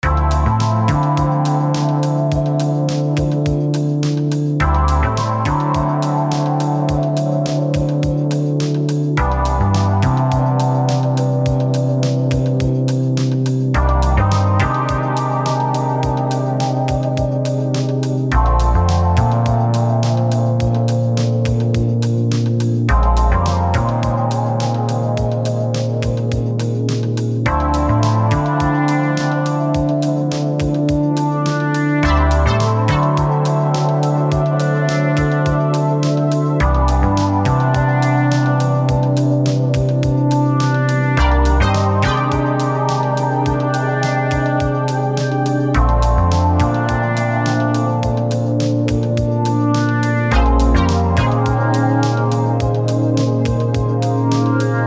Easily loopable track for your overpriced elevator :P